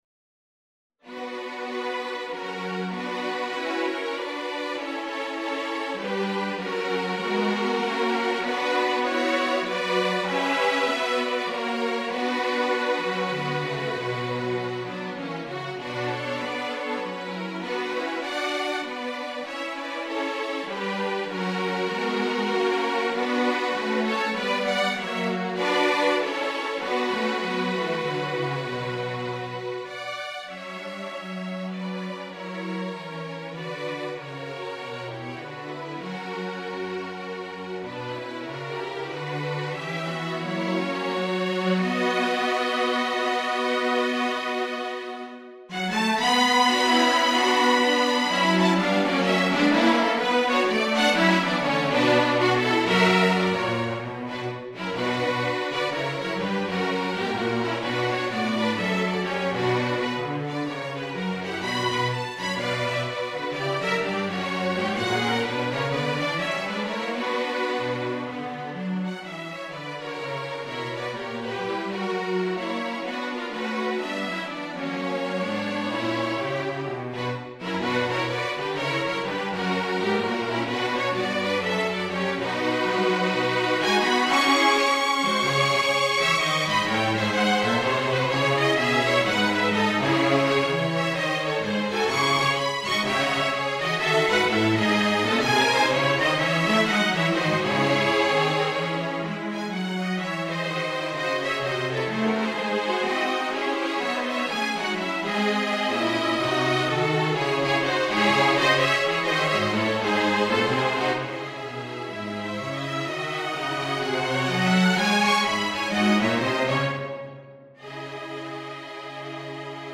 Jazz and Blues